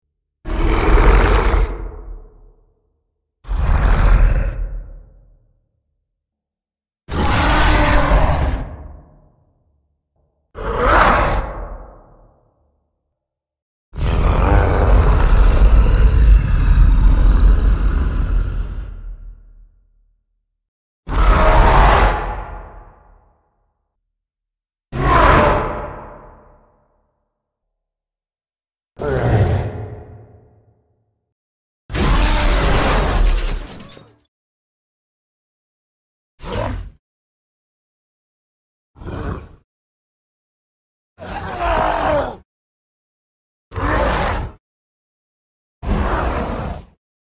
Звуки Халка
Скачивайте или слушайте онлайн его легендарный рык, яростные крики, разрушительные удары и угрожающее дыхание.